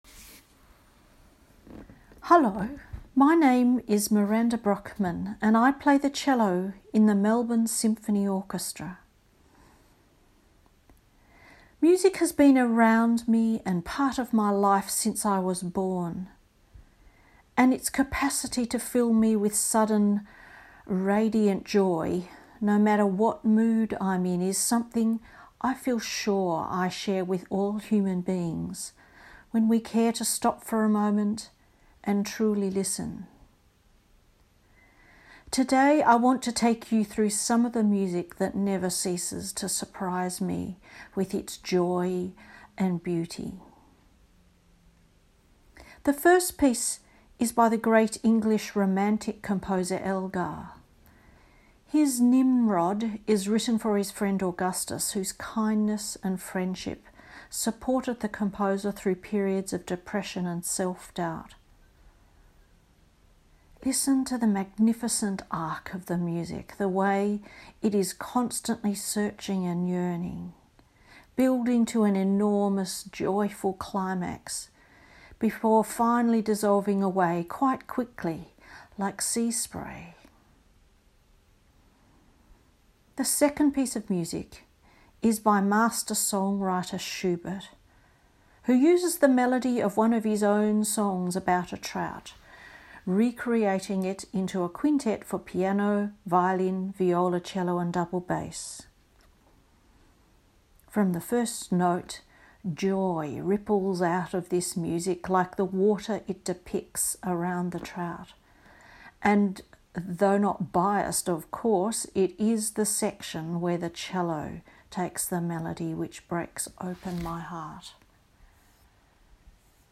Each musician has provided a short introduction to their playlist exploring the meaning the music has for them personally.